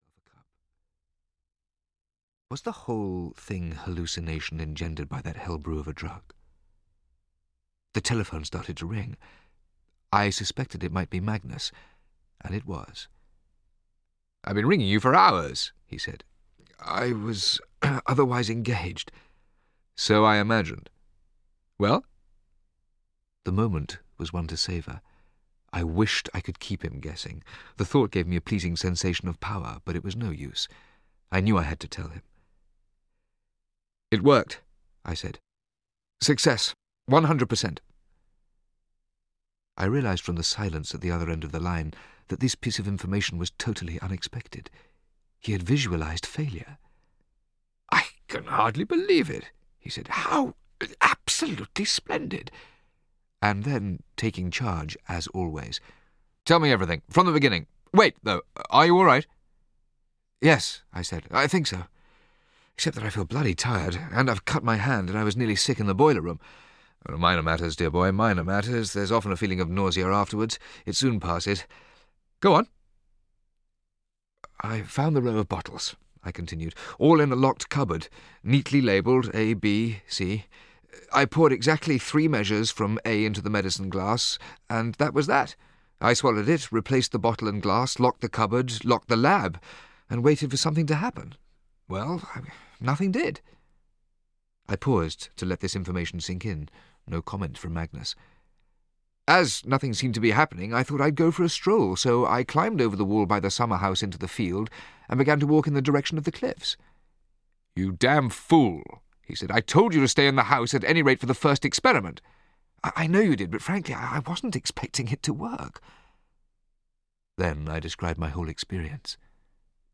Audio kniha
• InterpretMichael Maloney